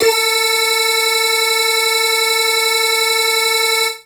55bg-syn18-a4.wav